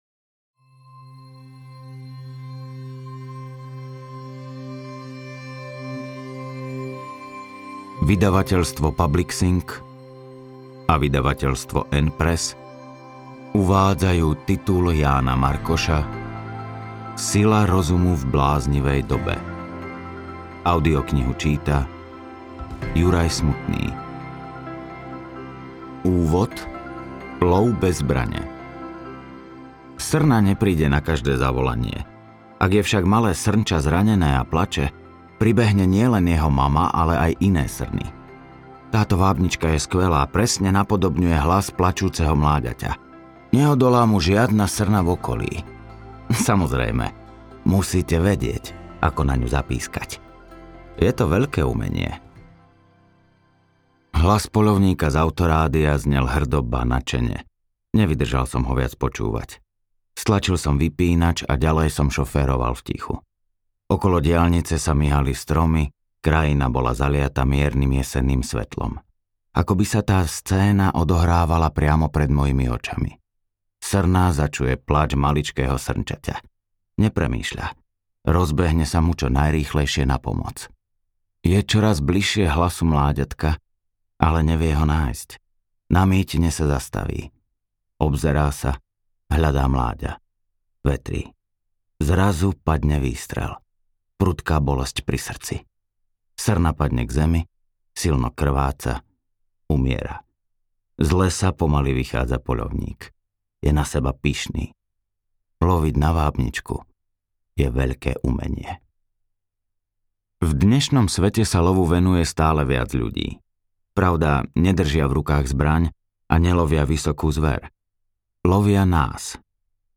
Audiokniha Sila rozumu